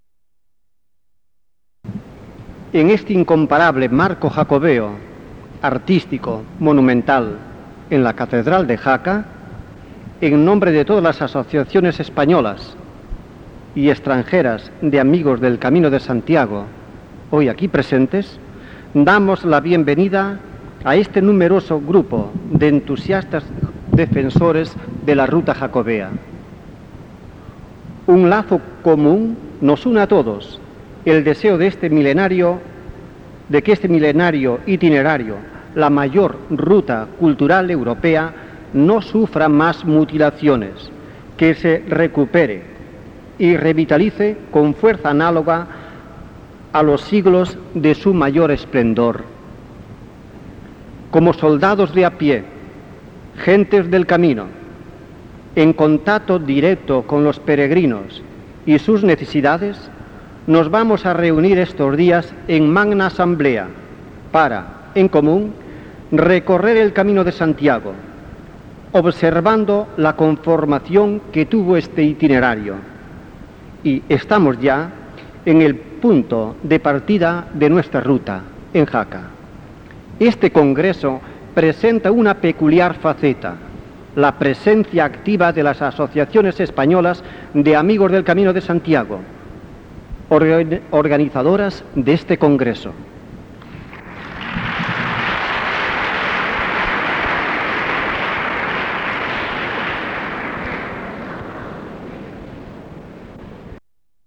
I Congreso Internacional de Jaca. Saludo de las Autoridades. Miércoles 23 de septiembre, S.I. Catedral de Jaca, 1987